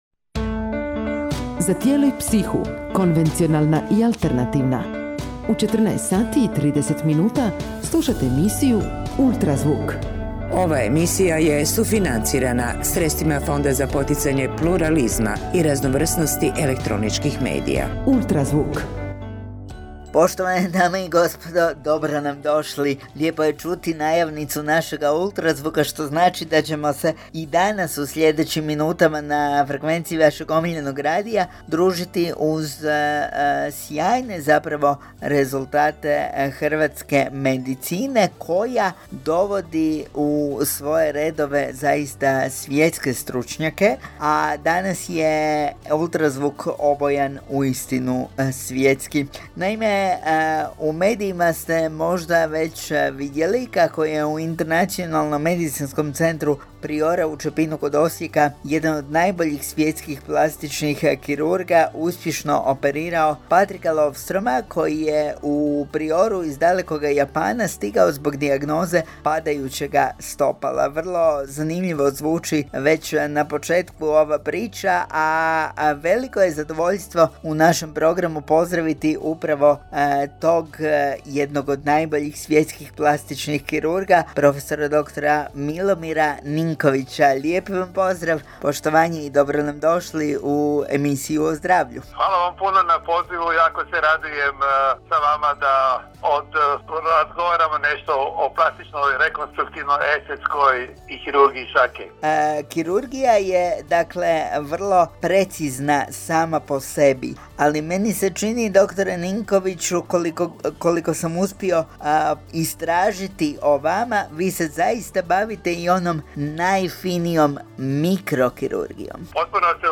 zdravstvenoj emisiji Ultrazvuk, koja se emitira na Radio 92FM Slavonski Brod. U razgovoru je istaknuo važnost mikrokirurgije u suvremenoj medicini te njezinu primjenu u složenim rekonstrukcijskim zahvatima koji pacijentima vraćaju funkciju i kvalitetu života.